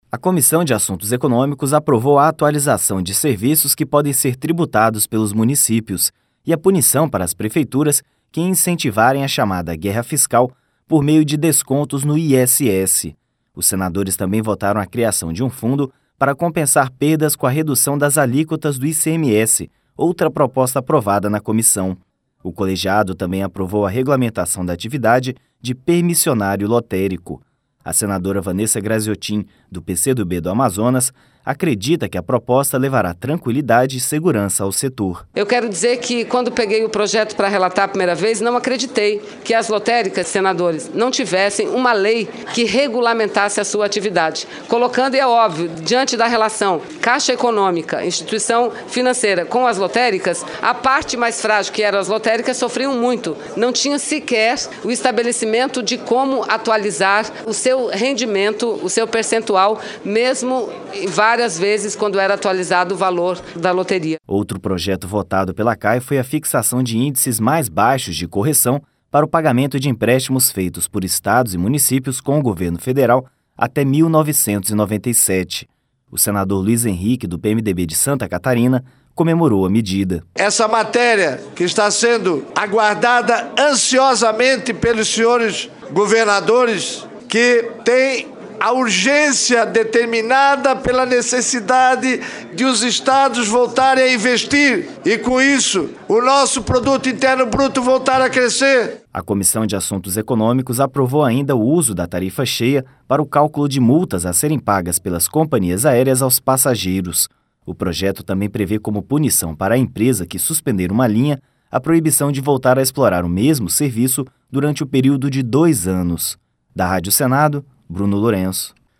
O senador Luiz Henrique, do PMDB de Santa Catarina, comemorou a medida.